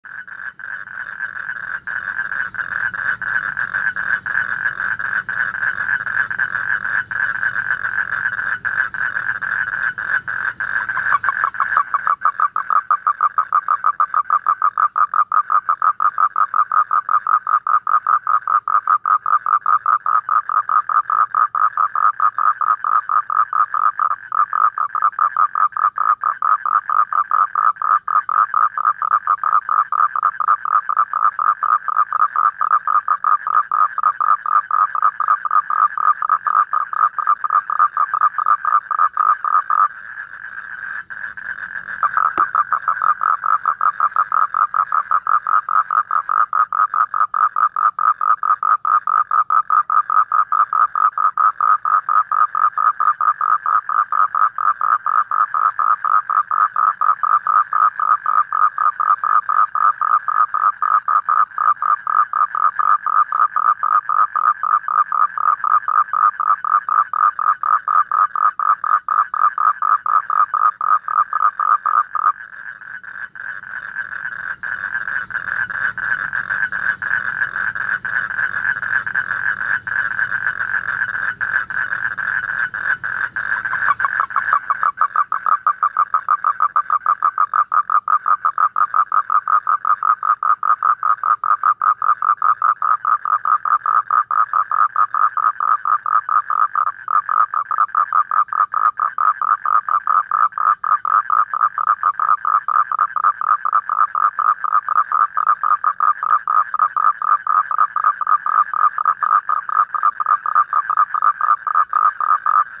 Tiếng Cóc kêu
Thể loại: Tiếng động vật hoang dã
Description: Trên đây là hiệu ứng âm thanh mp3 mô tả tiếng cóc kêu, âm thanh động vật như tiếng cóc nhái kêu vào ban đêm trên cánh đồng hoang vu, là âm thanh động vật ban đêm, âm thanh của tự nhiên hoang dã.
Tieng-coc-keu-www_tiengdong_com.mp3